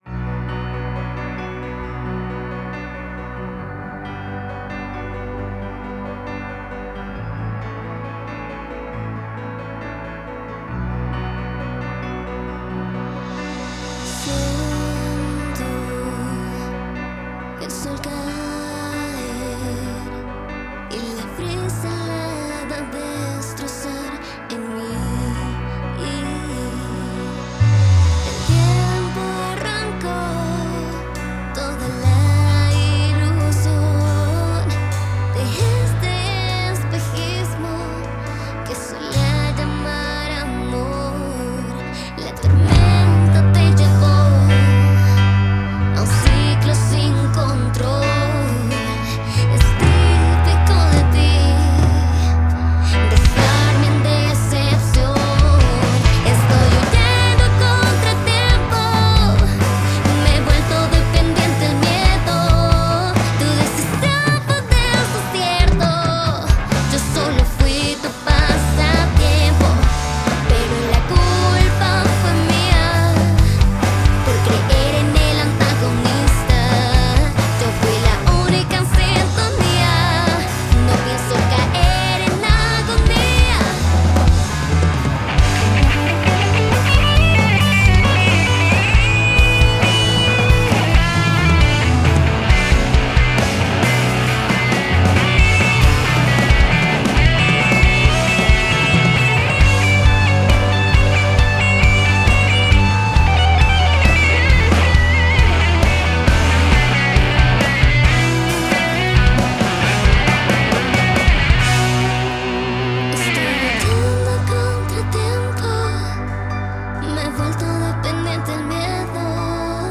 fusiona el pop rock con influencias de la balada pop